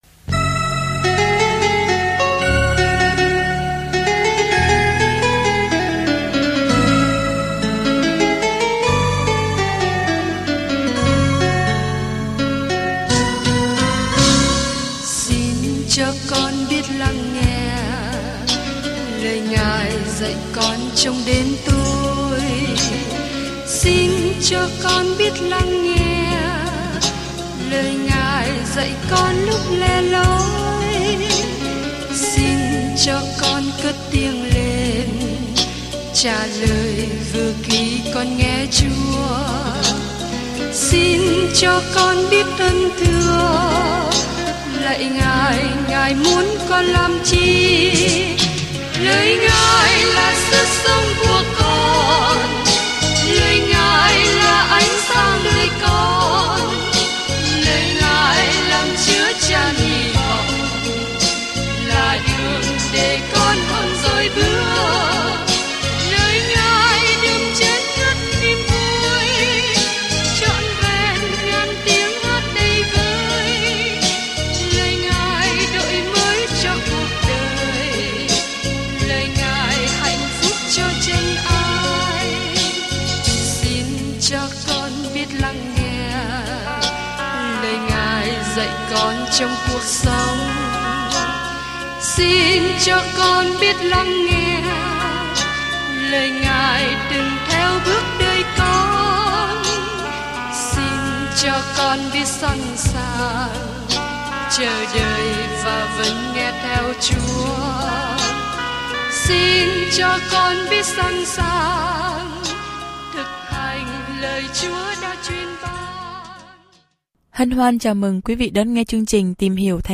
Kinh Thánh Truyền Đạo 10 Truyền Đạo 11 Ngày 7 Bắt đầu Kế hoạch này Ngày 9 Thông tin về Kế hoạch Truyền đạo là một cuốn tự truyện đầy kịch tính về cuộc đời của Sa-lô-môn khi ông cố gắng sống hạnh phúc mà không có Chúa. Du lịch hàng ngày qua Truyền đạo, bạn nghe nghiên cứu âm thanh và đọc những câu chọn lọc từ lời Chúa.